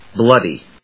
blood・y /blˈʌdi/